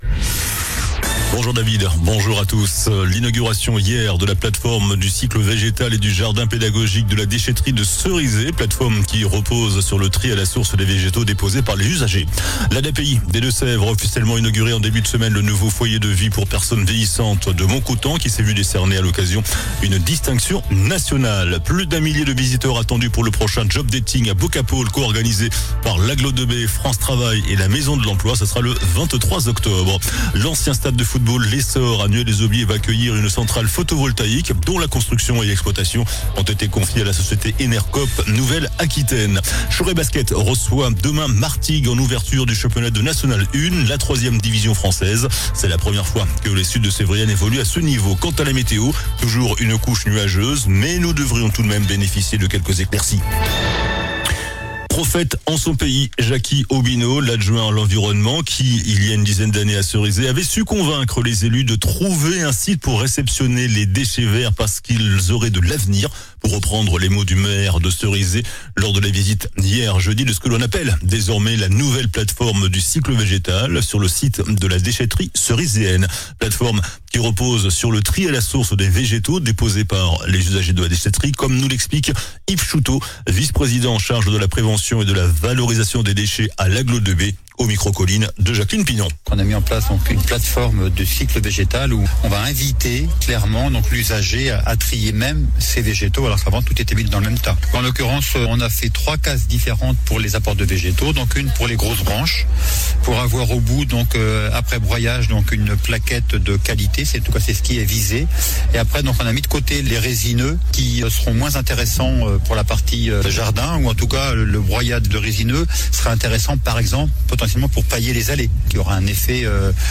JOURNAL DU VENDREDI 26 SEPTEMBRE ( MIDI )